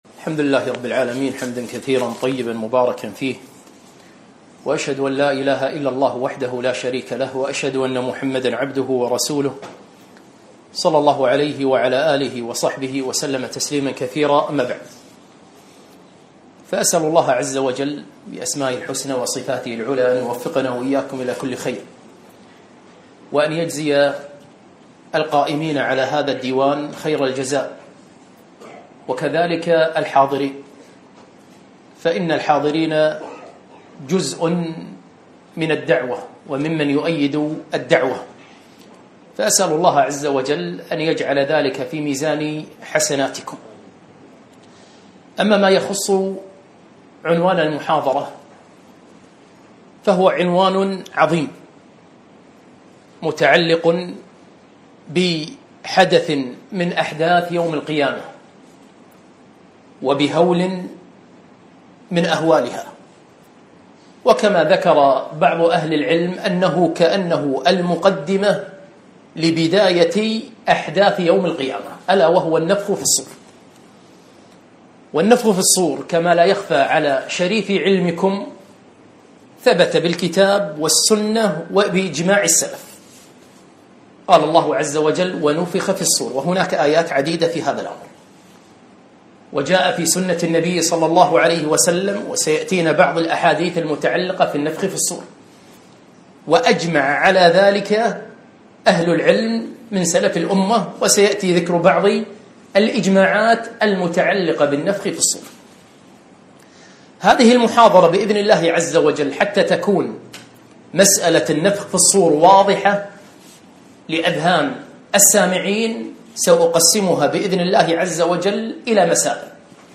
محاضرة - النفخ في الصور